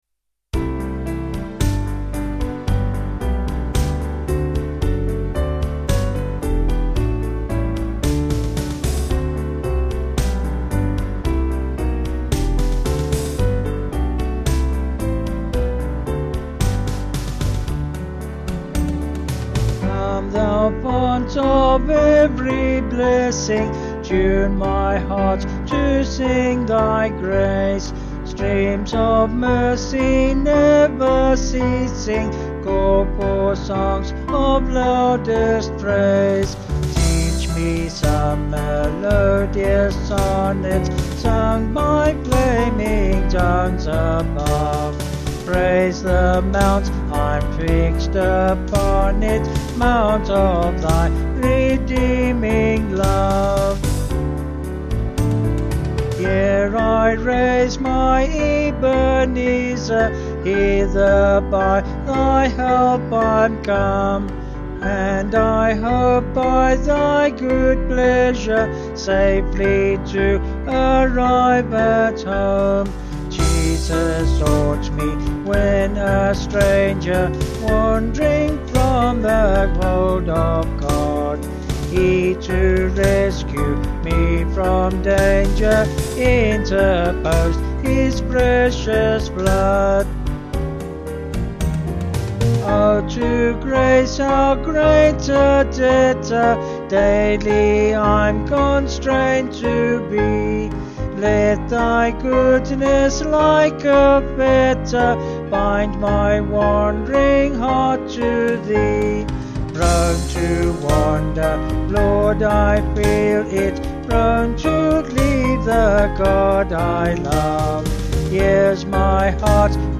Vocals and Band   263.8kb Sung Lyrics